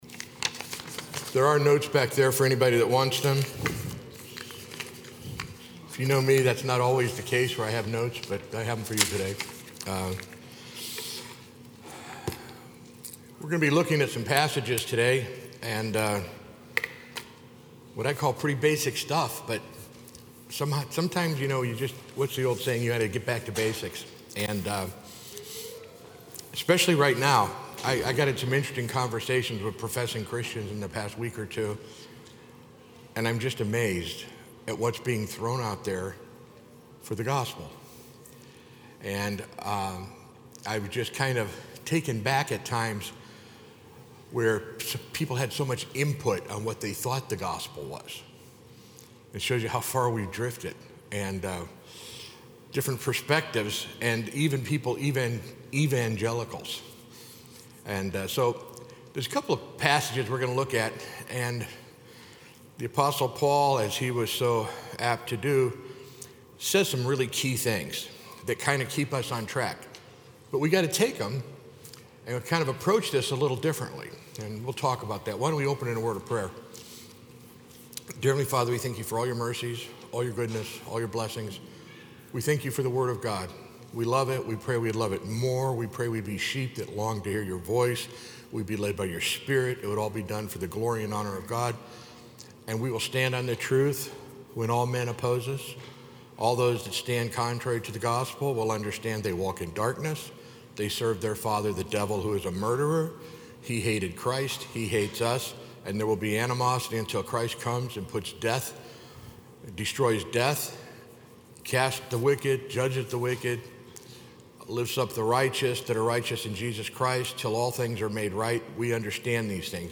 Sunday School - 10/1/2023